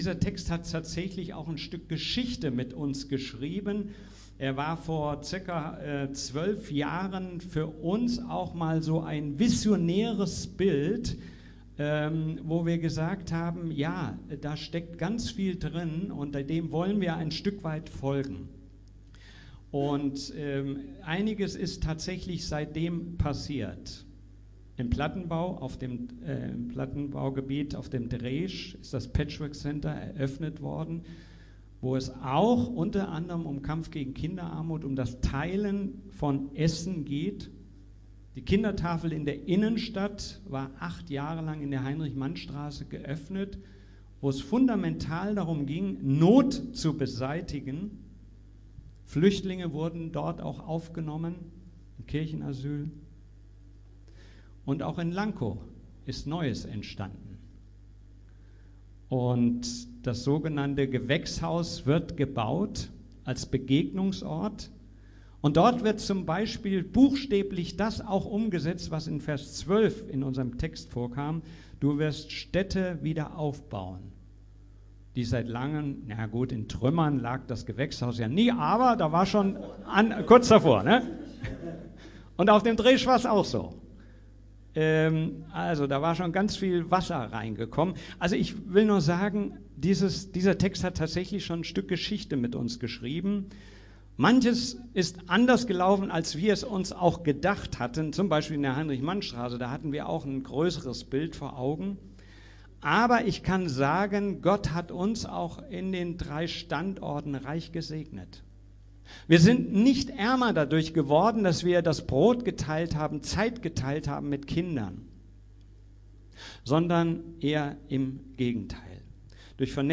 Predigten FeG Schwerin Podcast